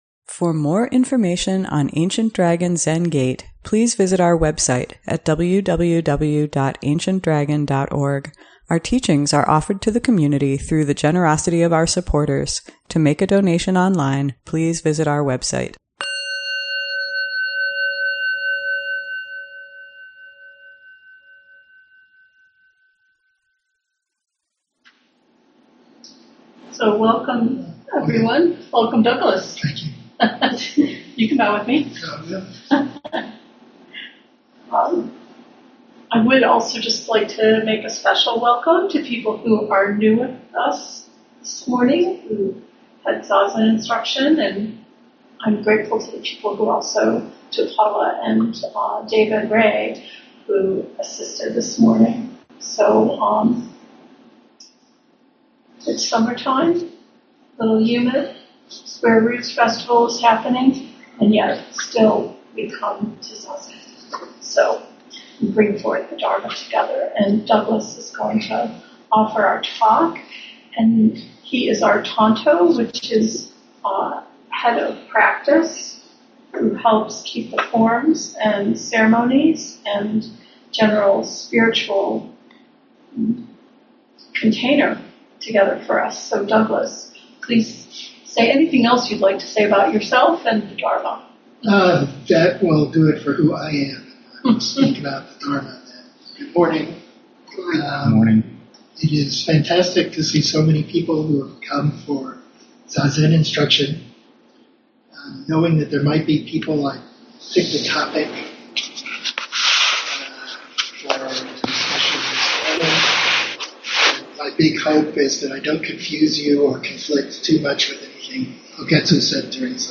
ADZG Sunday Morning Dharma Talk